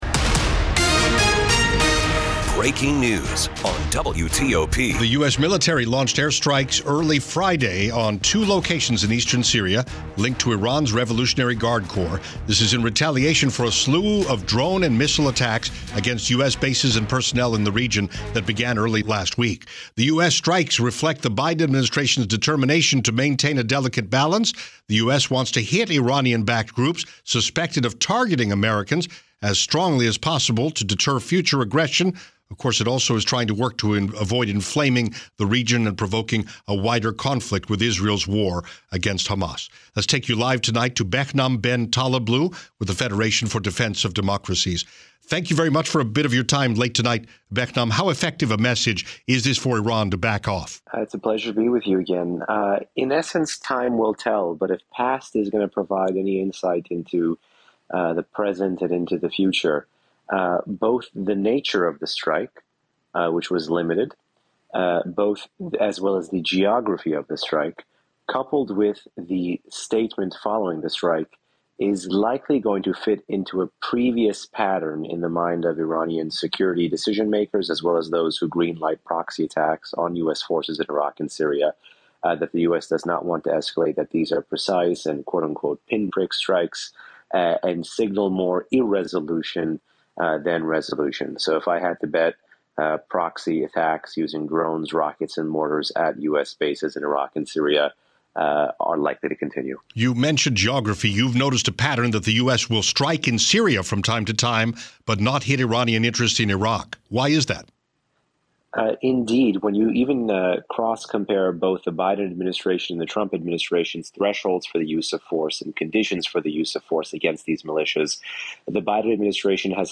WTOP’s Interviews and Links